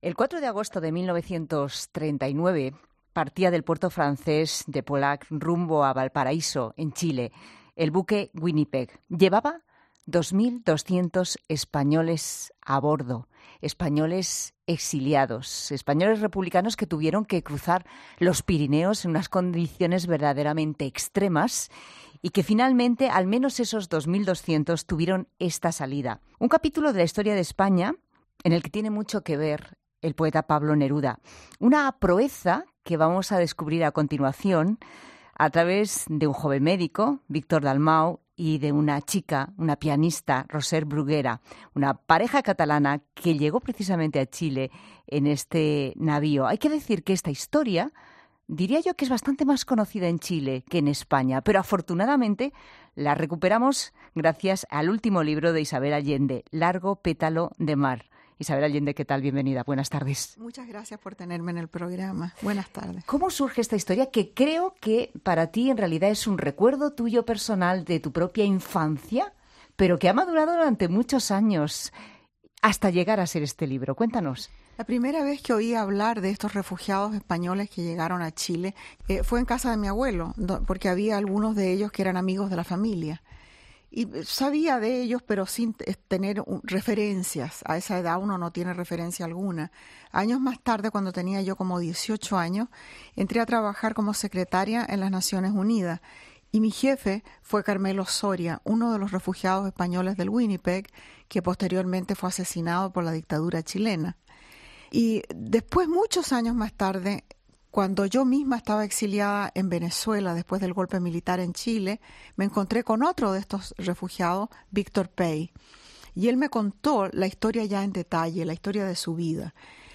ESCUCHA LA ENTREVISTA A ISABEL ALLENDE EN LA TARDE “Largo pétalo de mar” coincide con el 80 aniversario del viaje.